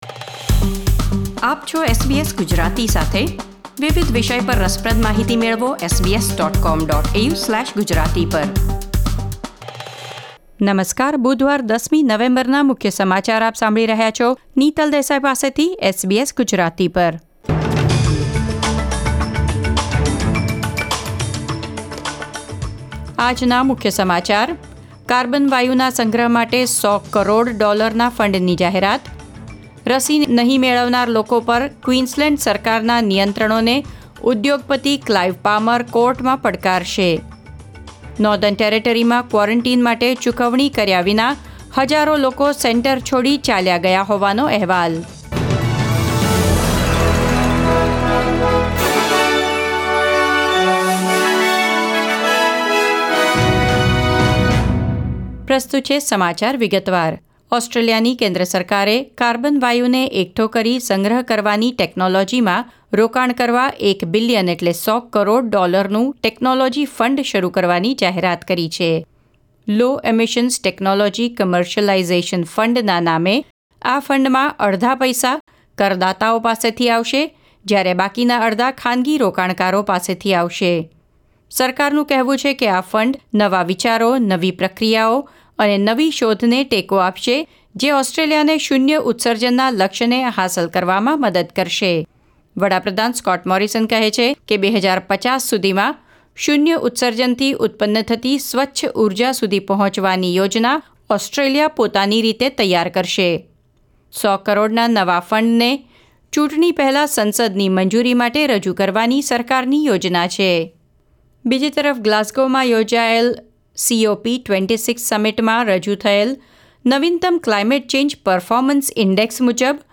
SBS Gujarati News Bulletin 10 November 2021